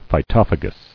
[phy·toph·a·gous]